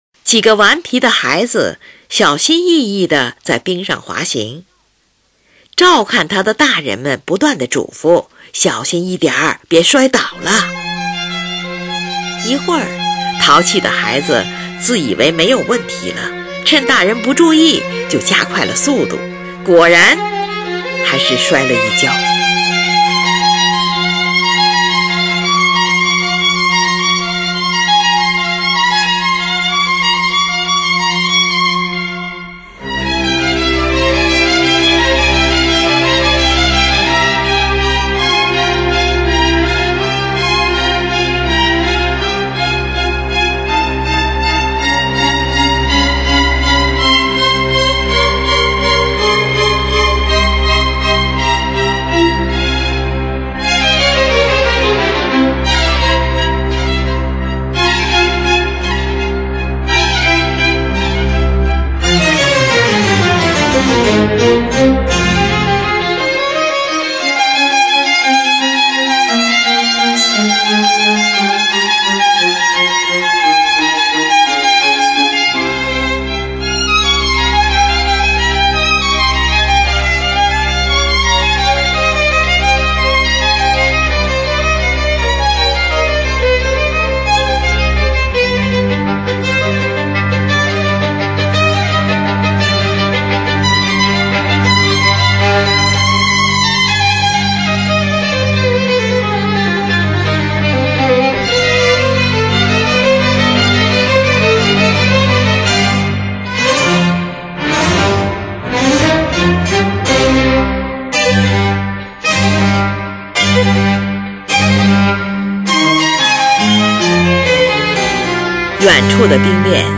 小提琴协奏曲
冬天--F小调
11. 广板，表现在火炉边过着宁静满足的岁月，屋外的雪水滋润着万物。